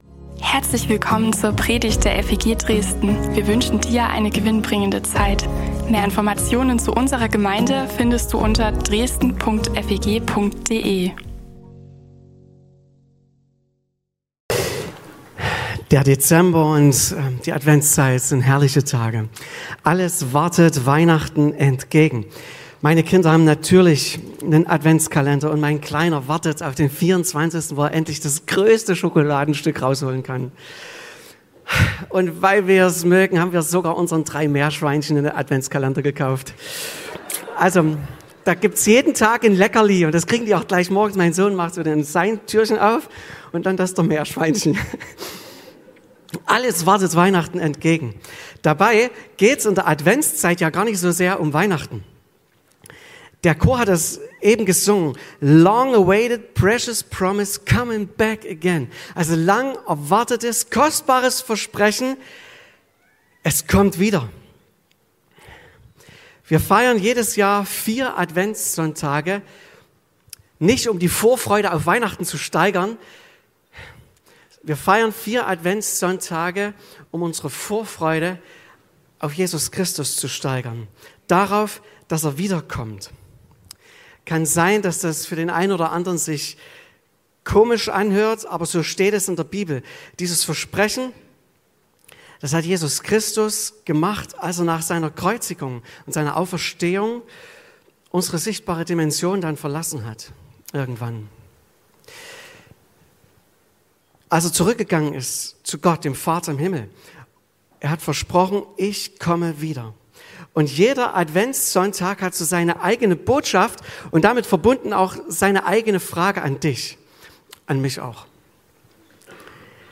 Gospel-Gottesdienst